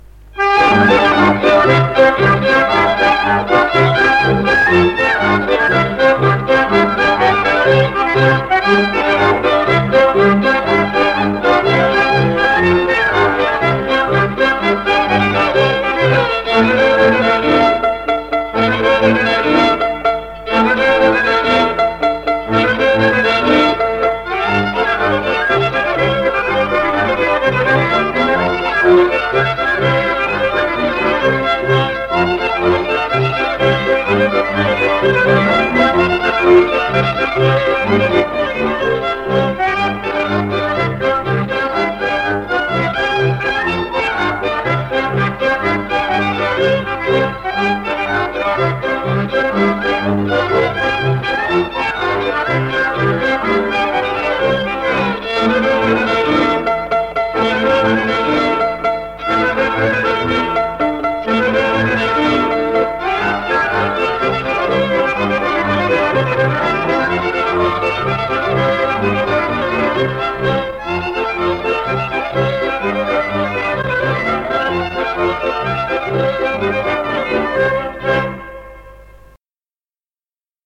Instrumentalny 7 (Polka zagłębiowska) – Żeńska Kapela Ludowa Zagłębianki
Nagranie archiwalne
Instrumentalny-7-Polka-zaglebiowska.mp3